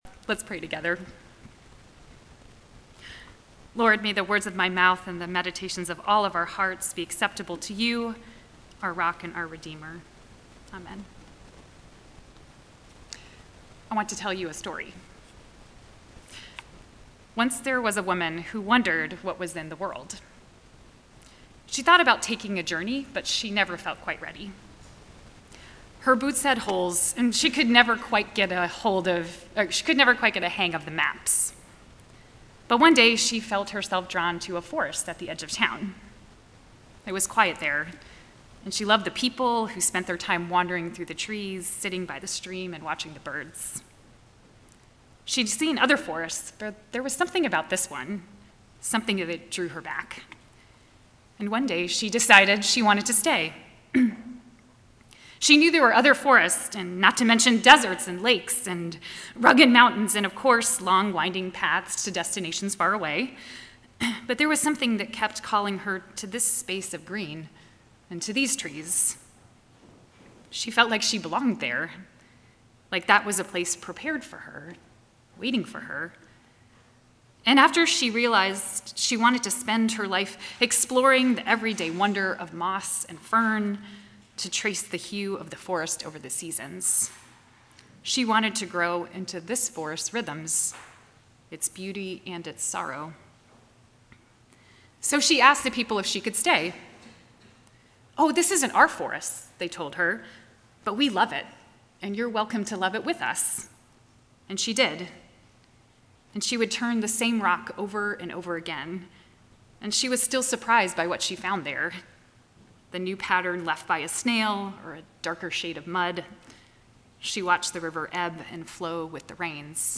Matthew 3:3-17 NOTE: There were electronic issues during the recording which caused interference in the audio. It gets progressively worse toward the end of the recording, but it’s a good sermon, so if you can persevere, it’s worth it! Baptism is a little death. At the same time, all of life is baptism.